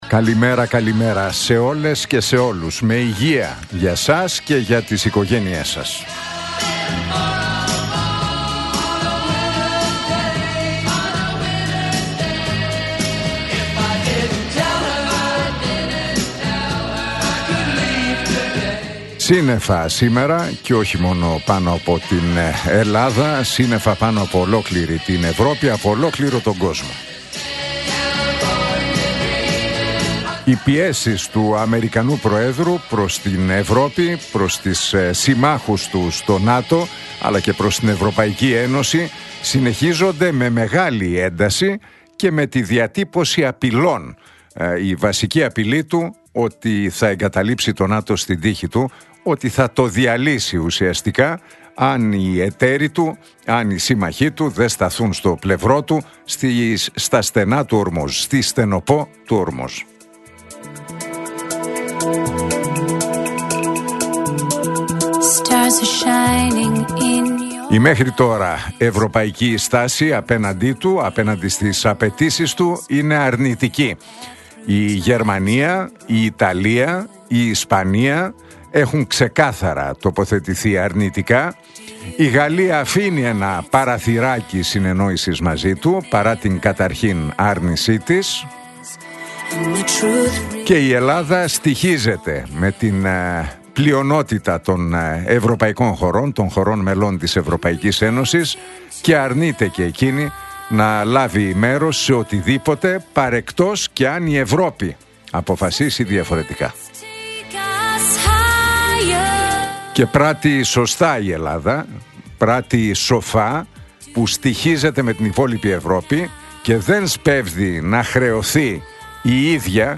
Ακούστε το σχόλιο του Νίκου Χατζηνικολάου στον ραδιοφωνικό σταθμό Realfm 97,8, την Τρίτη 17 Μαρτίου 2026.